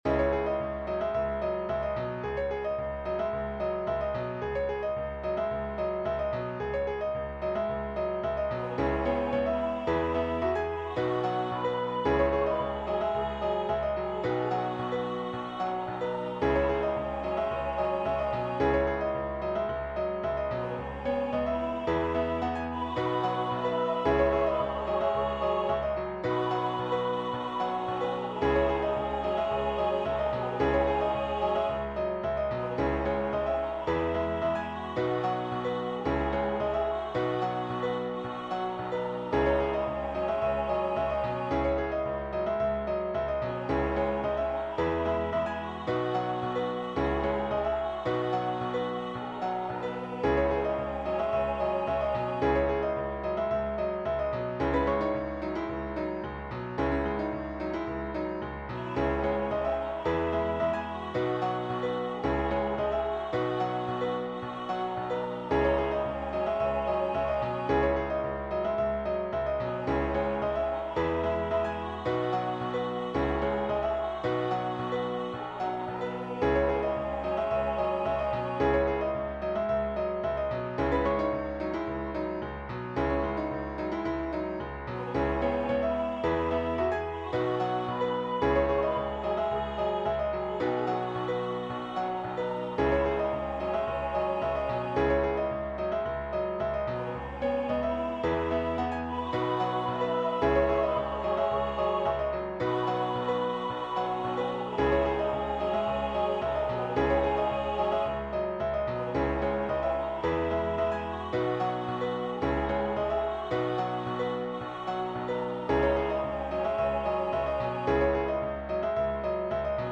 This arrangement is for voice and piano.